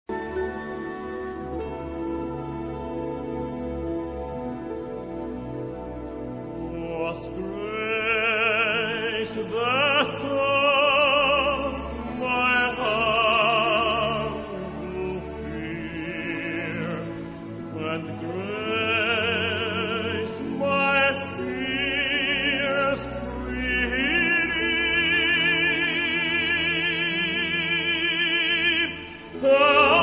key: A-major